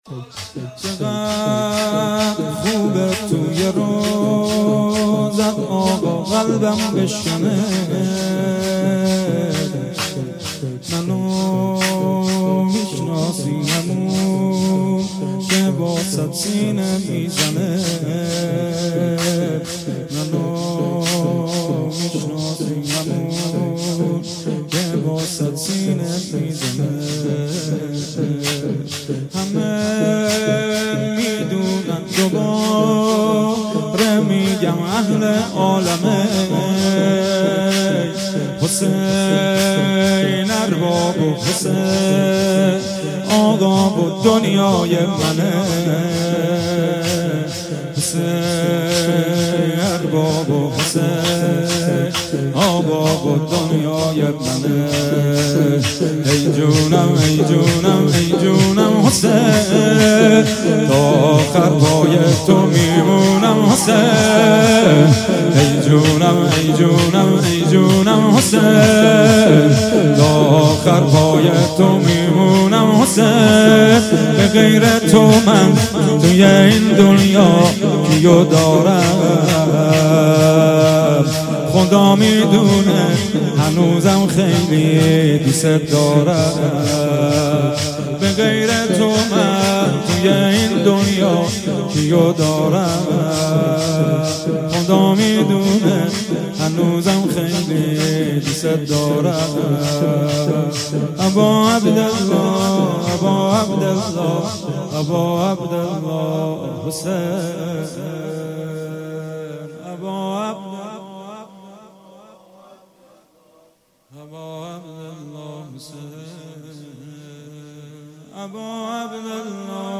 چقدر خوبه توی روضه _ شور
روضه حضرت صدیقه شهیده علیها سلام